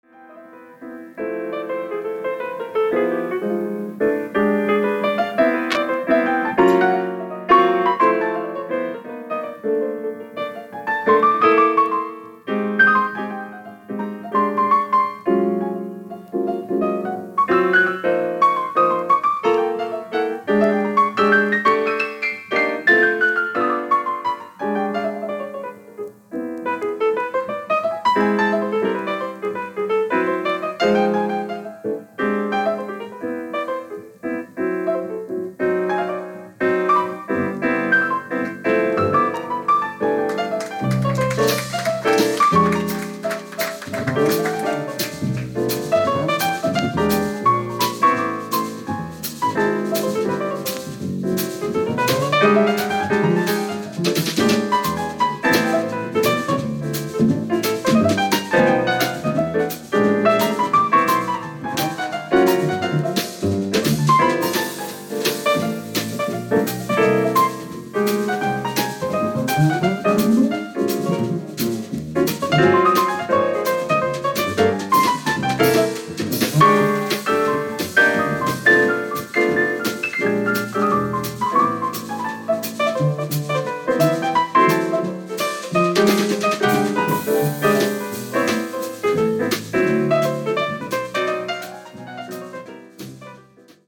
７４年・Village Vanguardでの録音です。